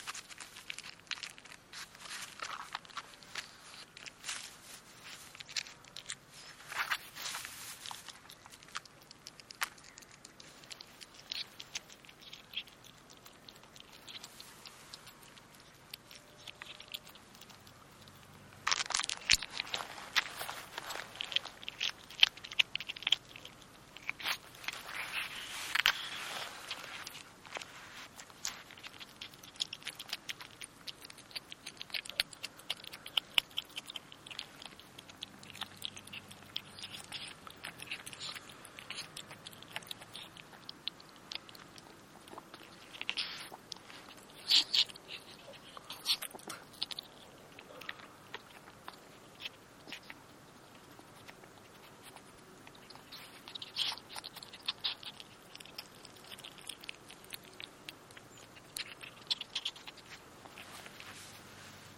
Звуки, издаваемые хорьками можете послушать онлайн, а при необходимости загрузить на телефон, планшет или компьютер бесплатно.
4. Как хорек кушает